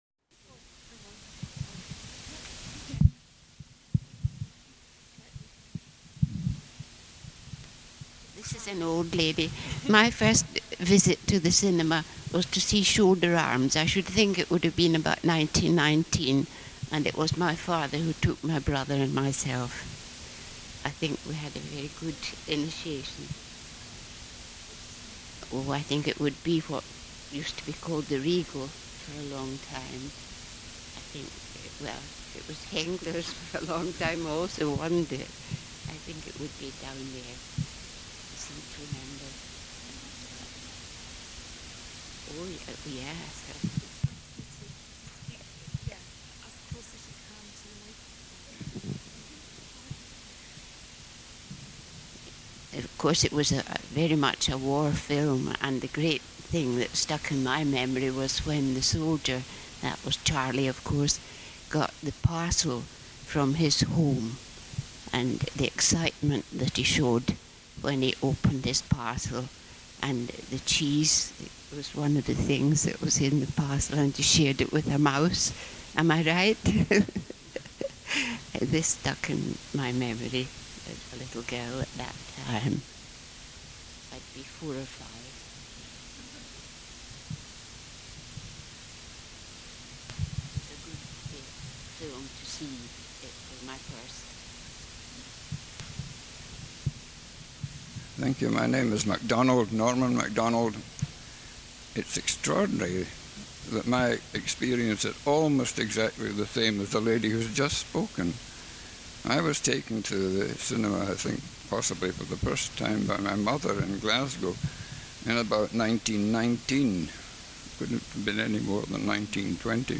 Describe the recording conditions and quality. Sound Quality: Poor ******************************************************** [Start of workshop recording] [inaudible] F1: This is an old lady.